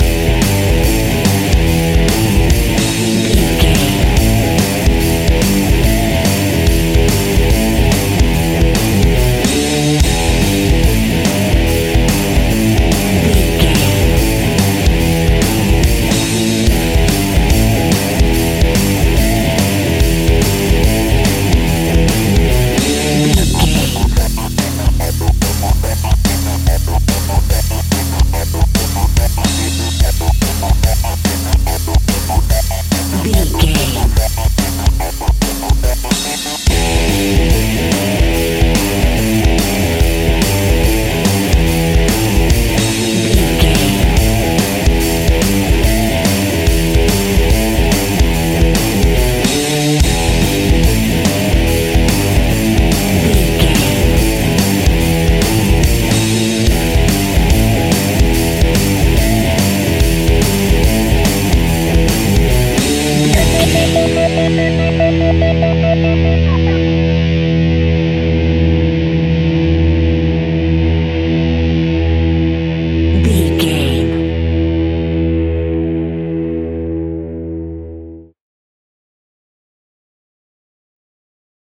Epic / Action
Aeolian/Minor
heavy metal
blues rock
distortion
instrumentals
rock guitars
Rock Bass
Rock Drums
heavy drums
distorted guitars
hammond organ